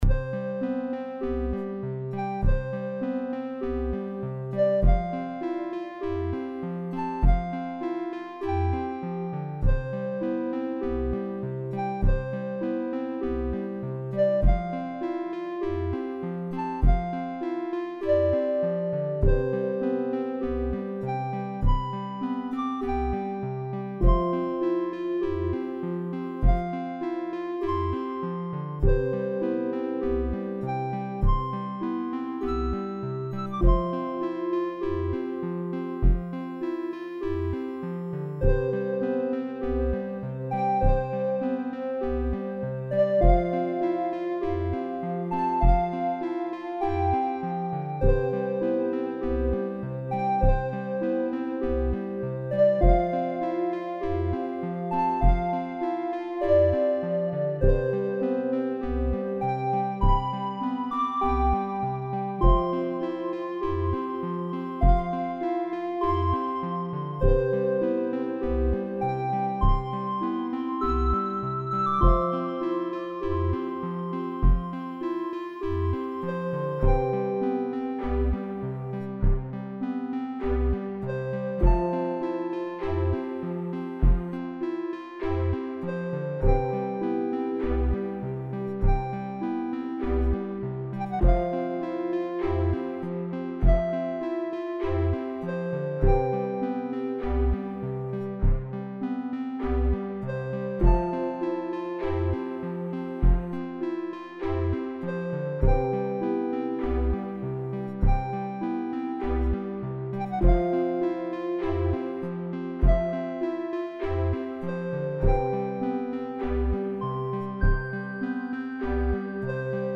Relaxing chiptune Music